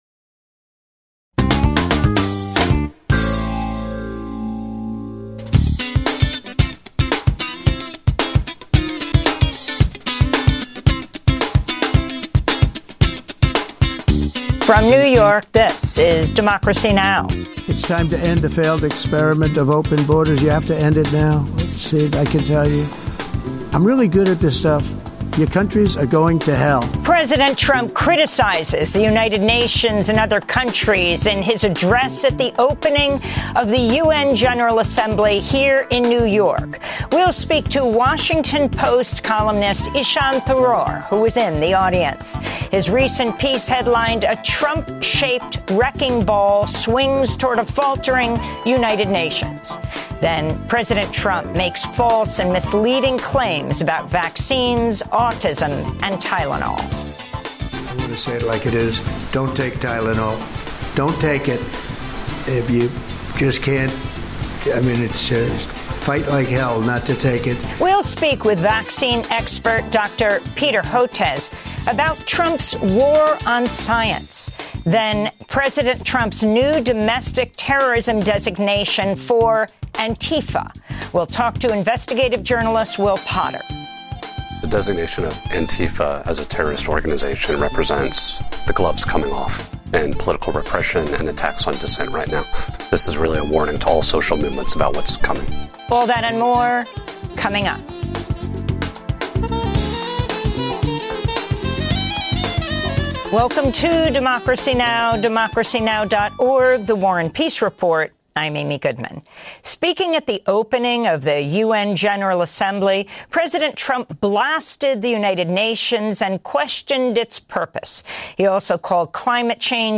daily, global, independent news hour